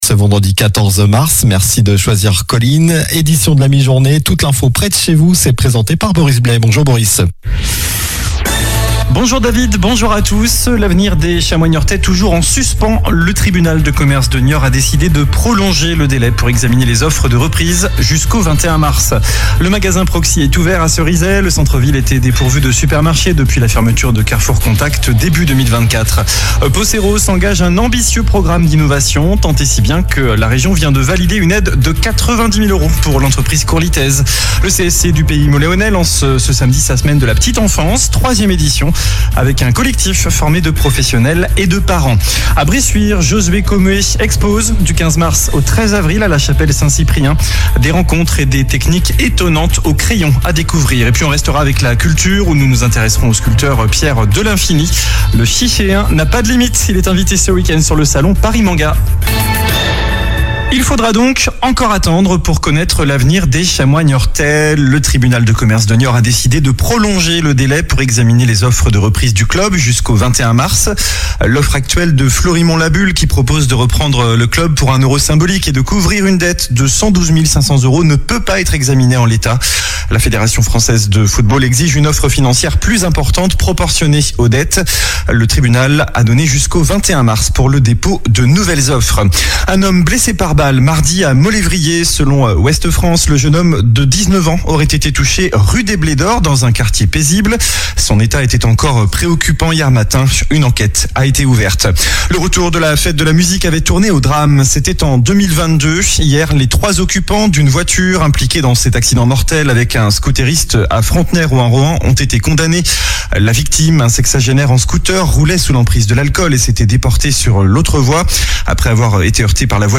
Journal du vendredi 14 mars (midi)